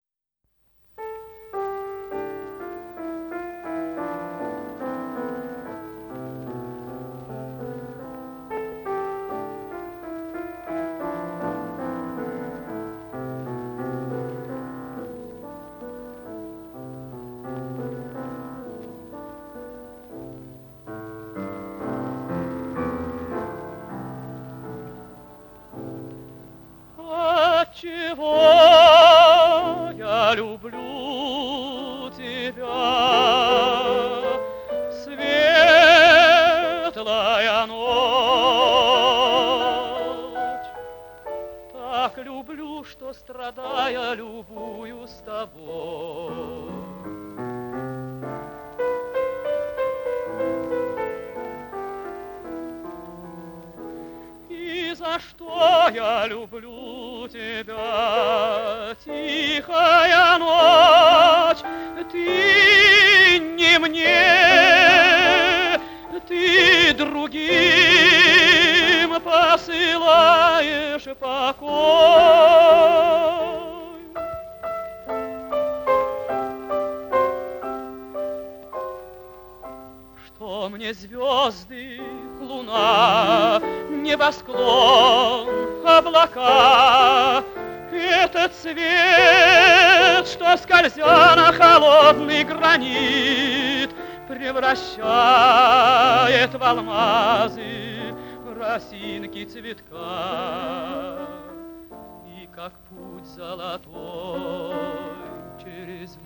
Прослушивание аудиозаписи романса «Ночь». Музыка П. И. Чайковского, исполнитель С. Лемешев.